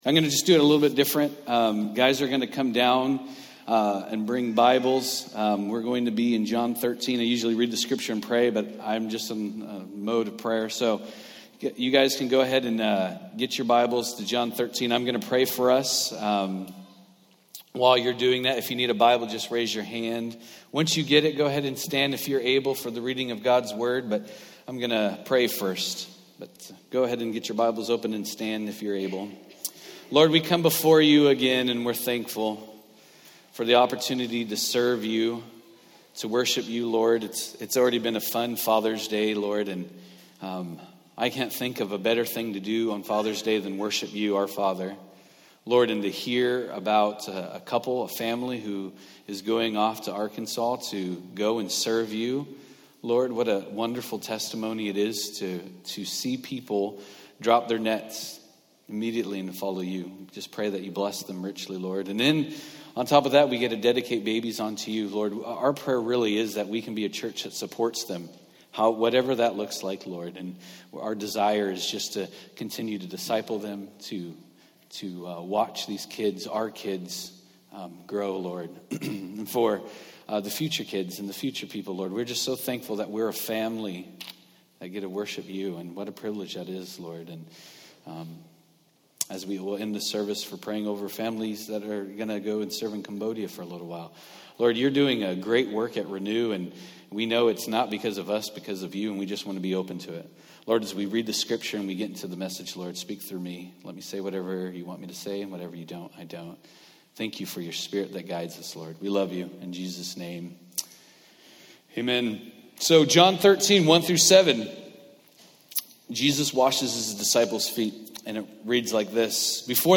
Passage: John 13:1-17 Service Type: Sunday Morning